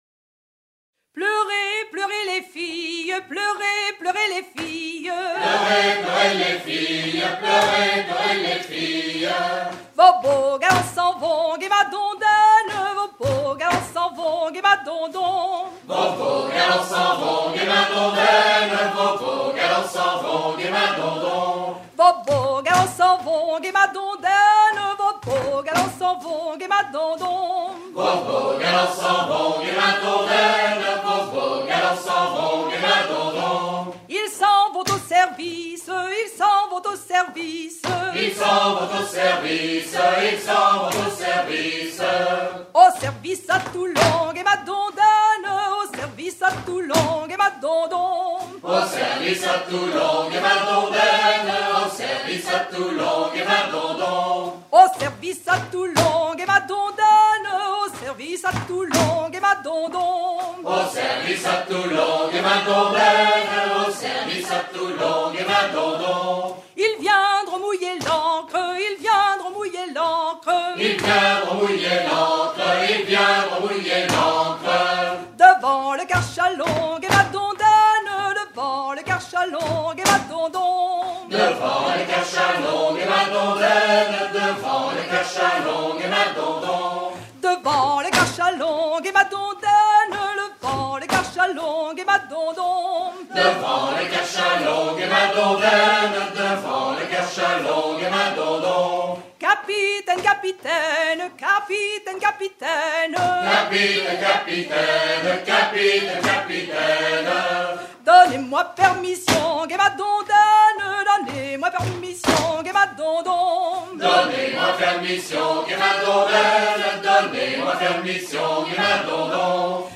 danse : ronde : rond de l'Île d'Yeu
Pièce musicale éditée